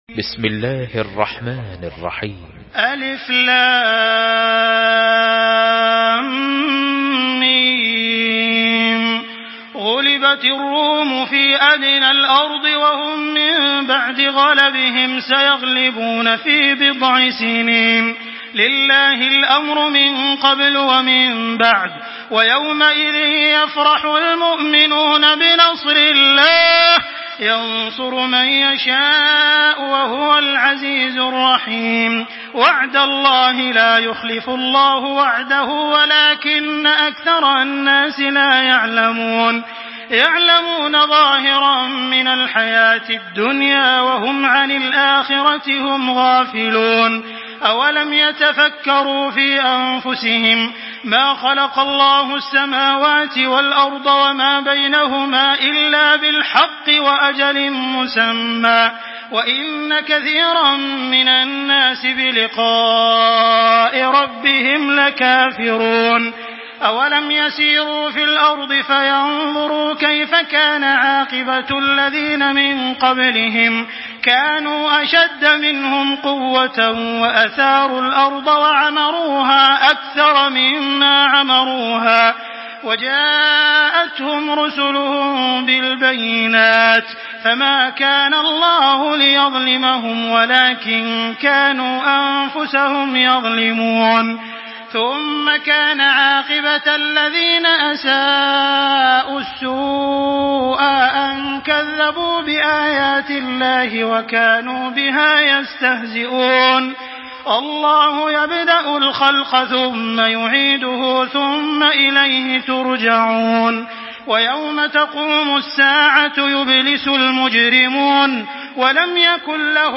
تحميل سورة الروم بصوت تراويح الحرم المكي 1425
مرتل حفص عن عاصم